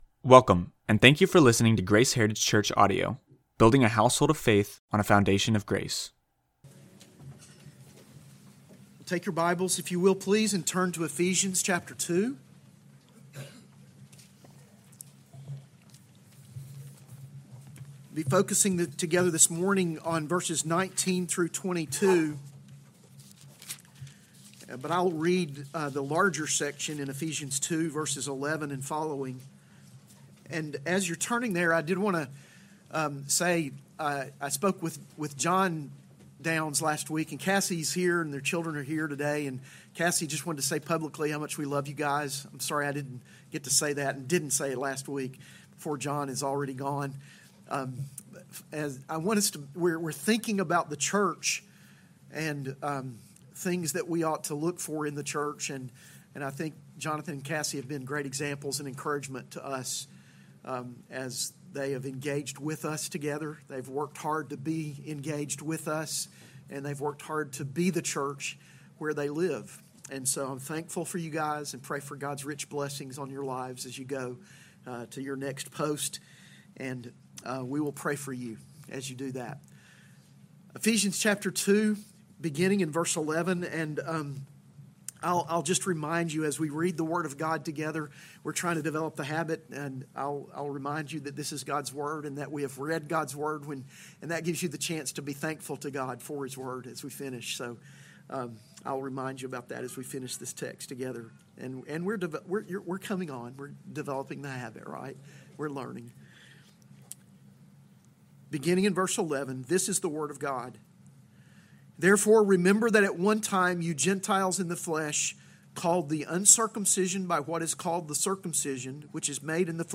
AM Worship Sermon